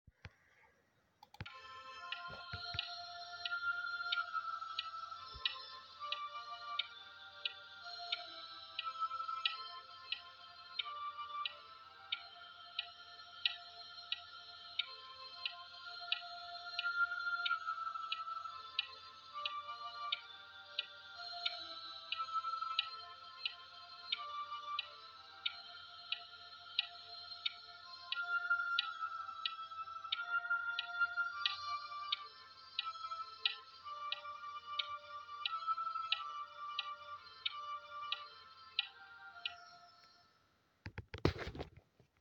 • Catégorie : Chants d’Offertoire